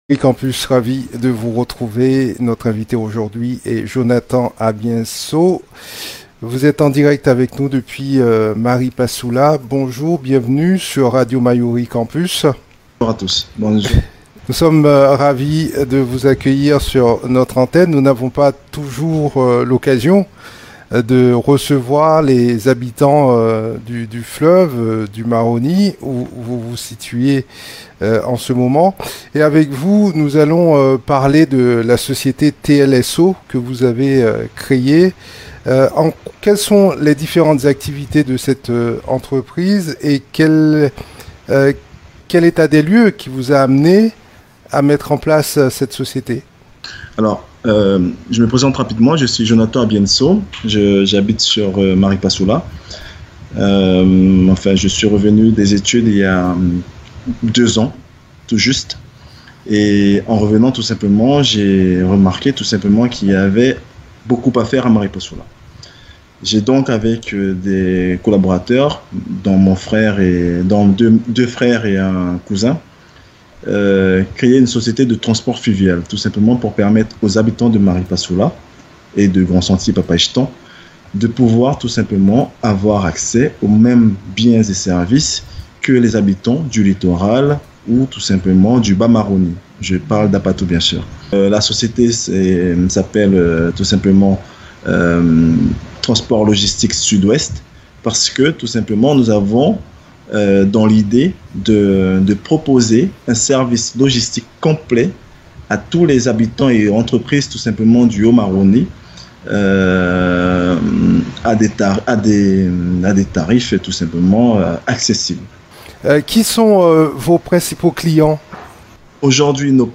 Depuis Maripasoula, en direct, il répond à nos questions.